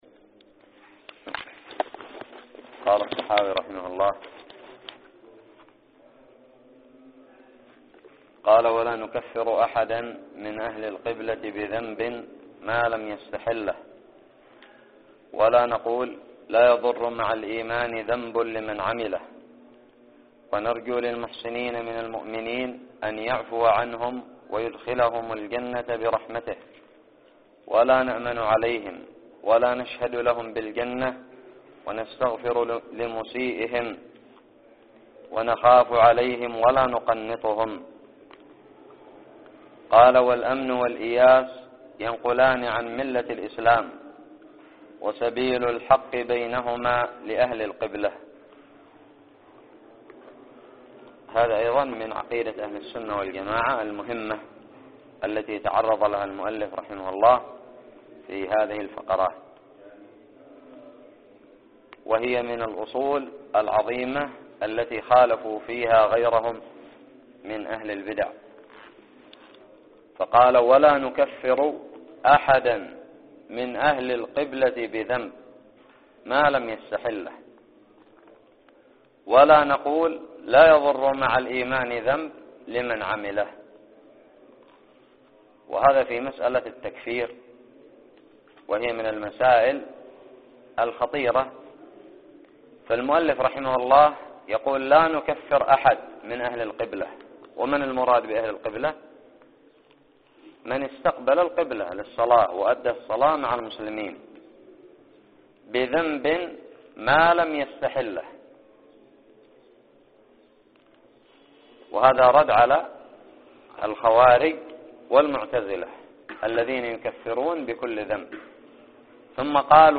ألقيت في دار الحديث بدماج